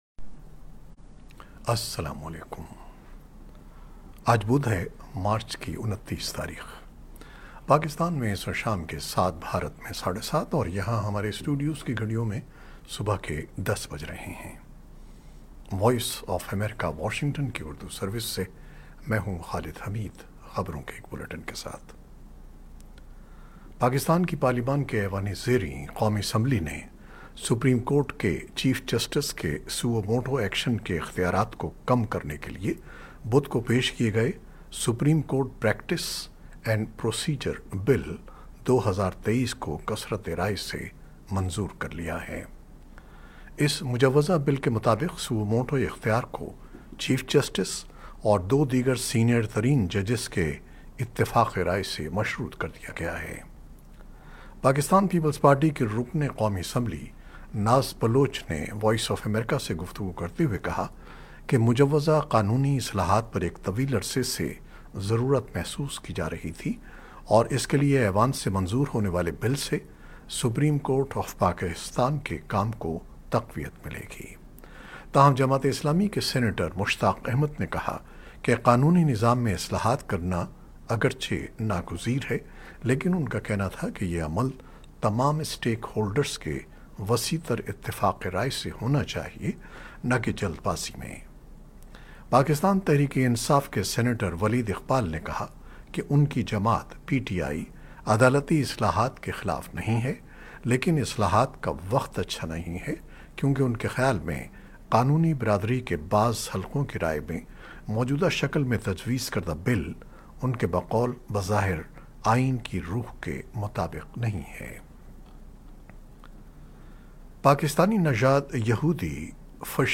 شام سات بجے کی خبریں